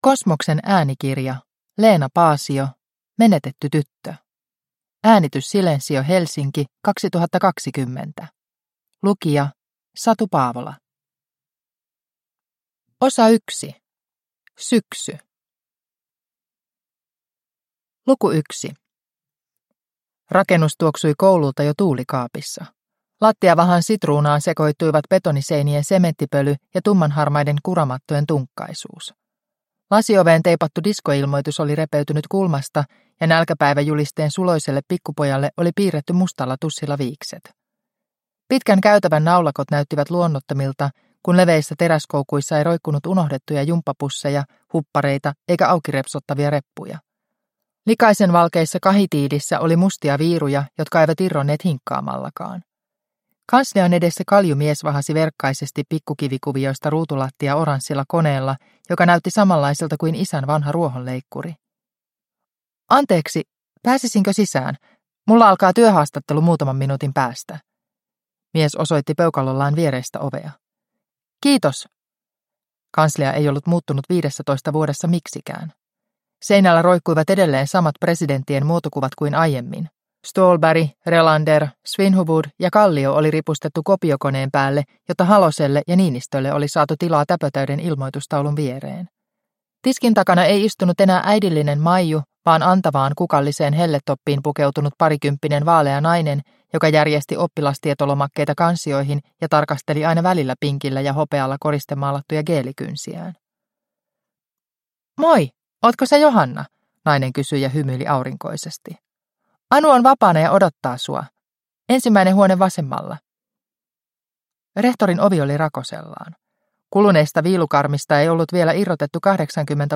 Menetetty tyttö – Ljudbok – Laddas ner